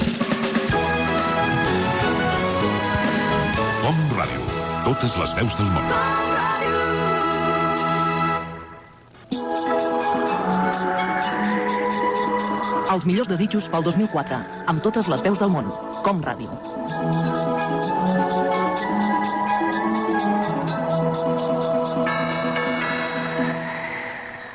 Indicatiu i desig de bon any 2004